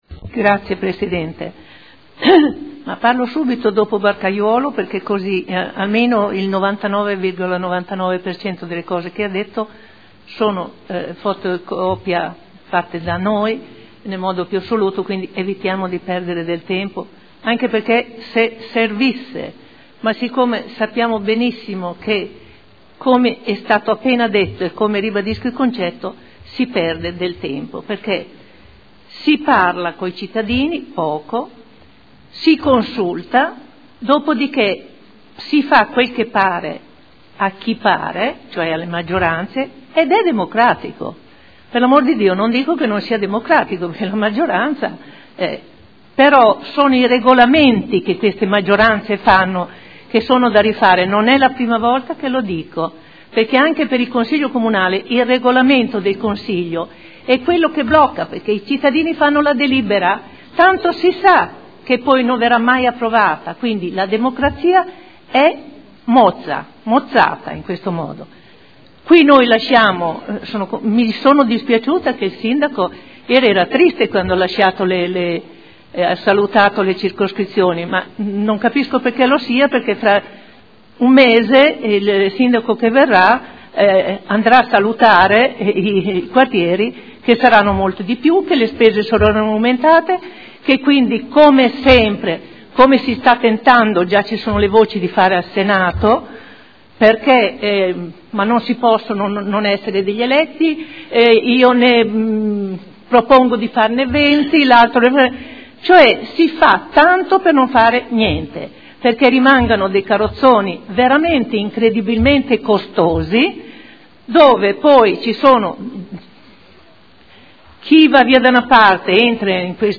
Olga Vecchi — Sito Audio Consiglio Comunale